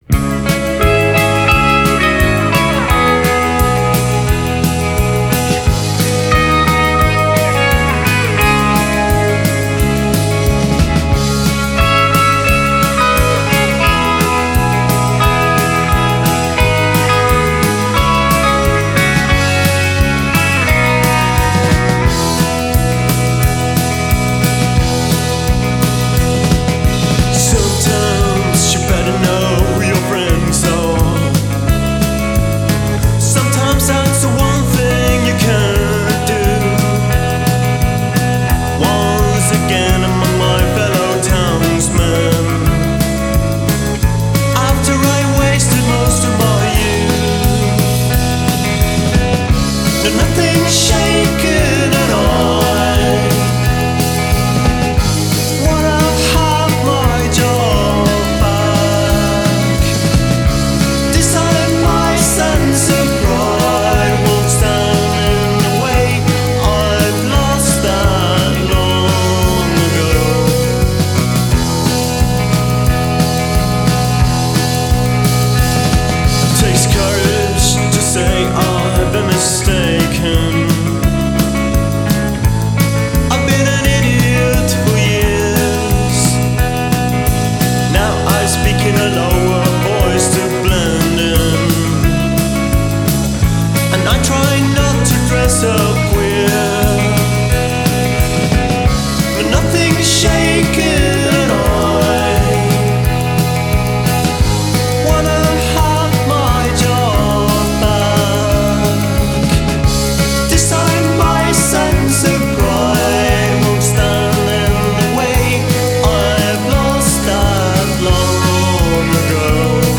acoustic guitar, vocals
electric guitar, backing vocals
bass guitar
keyboards, backing vocals
drums
Genre: Indie Pop / Twee